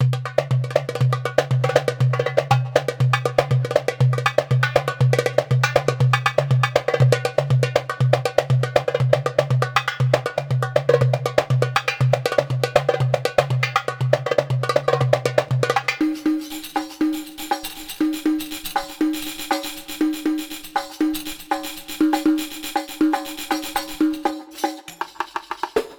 Für die Klangbeispiele habe ich verschiedene MIDI Dateien mit den entsprechenden Instrumenten aufgenommen.
Die Klänge der einzelnen Instrumente sind authentisch und mit Hilfe der vorhandenen MIDI Dateien können schnell tolle Rhythmen erstellt werden.
arabic-percussion-2.mp3